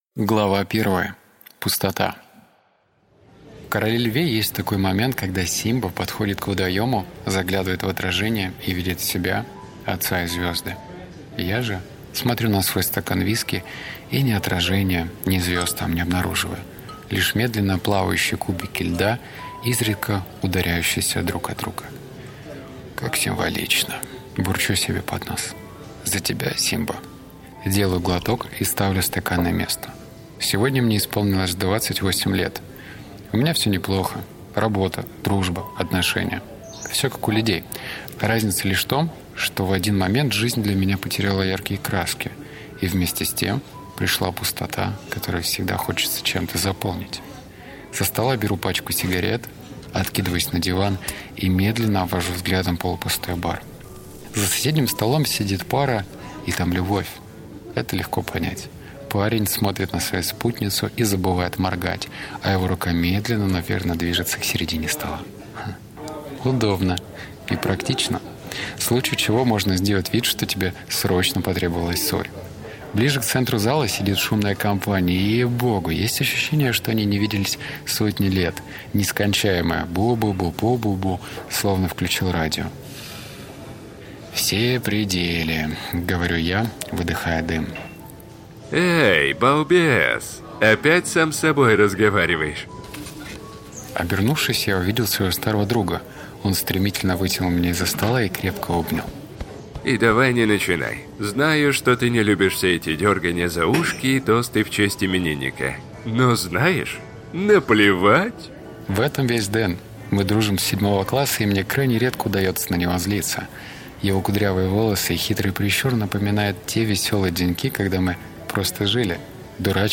Аудиокнига Судьба шлёт знаки, или На …